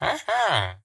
minecraft / sounds / mob / villager / yes2.ogg
yes2.ogg